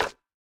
resin_brick_place3.ogg